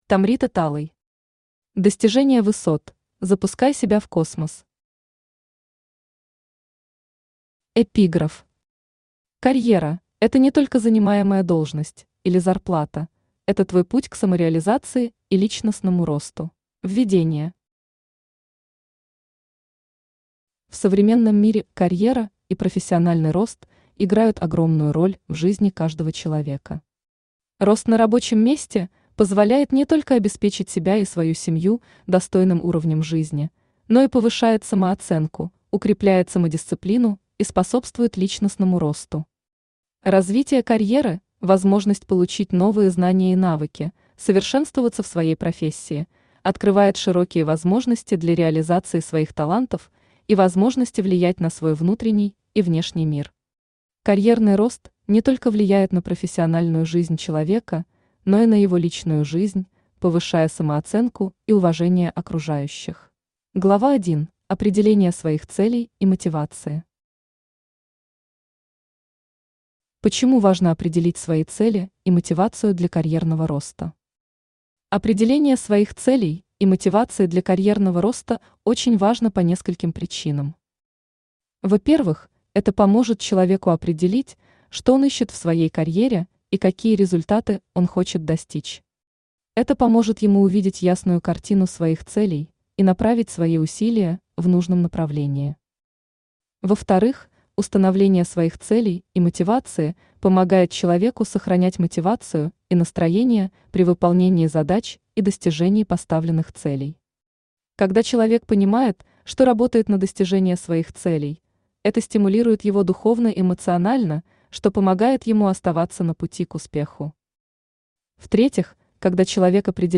Аудиокнига Достижение высот: Запускай себя в космос | Библиотека аудиокниг
Aудиокнига Достижение высот: Запускай себя в космос Автор Tomrita Talay Читает аудиокнигу Авточтец ЛитРес.